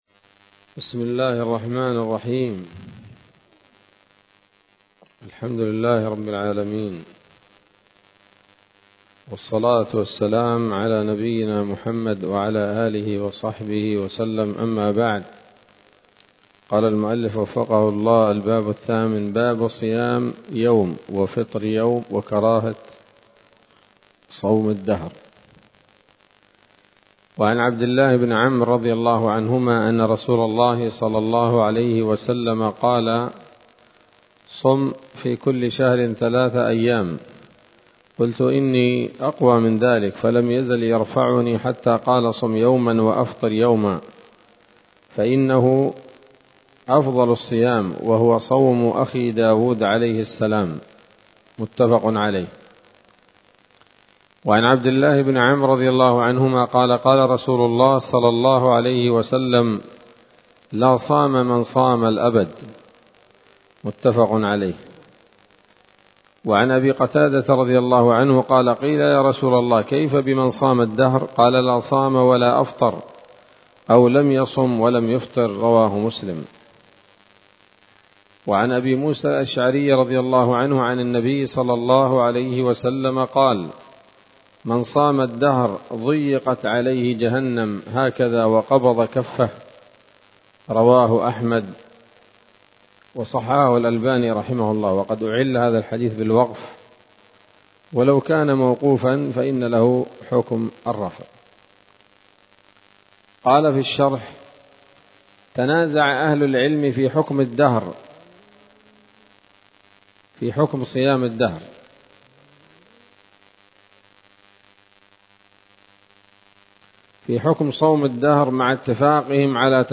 الدرس السادس والعشرون من كتاب الصيام من نثر الأزهار في ترتيب وتهذيب واختصار نيل الأوطار